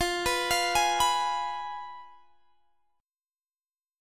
F Diminished